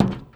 Footstep_Metal 04.wav